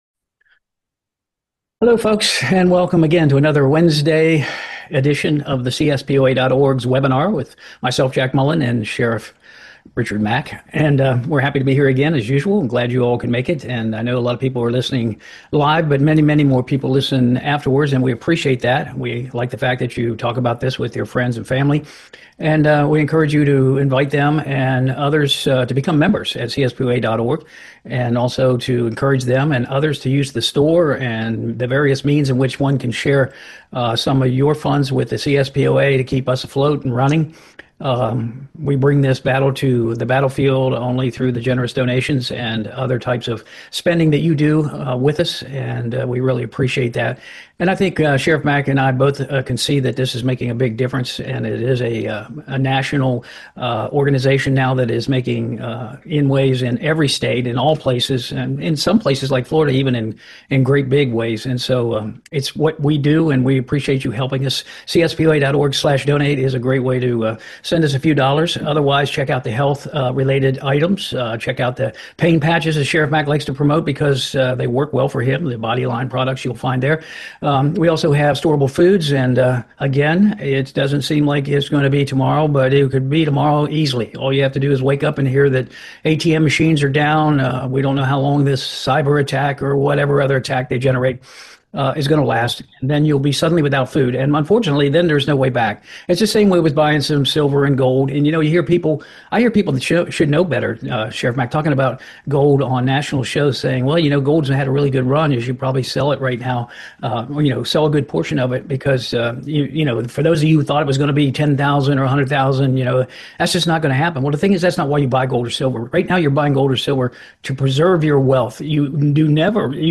webinar